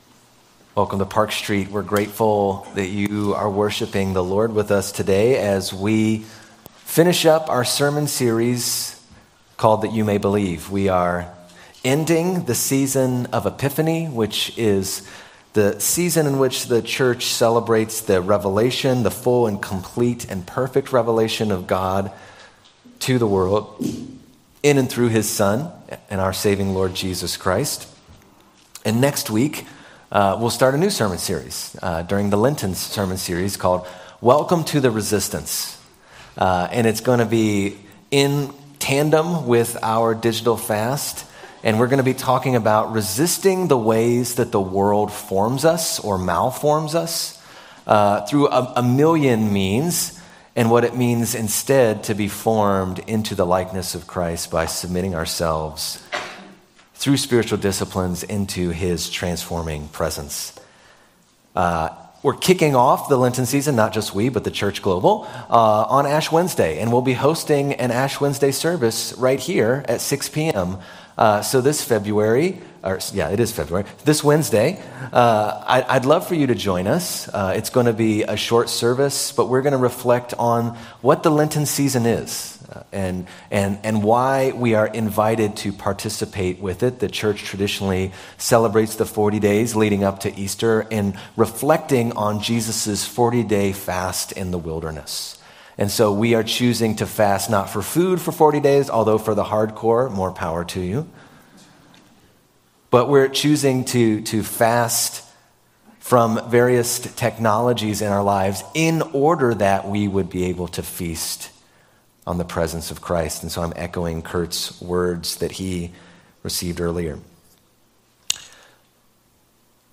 Sermons - Park Street Brethren Church
Sermon Series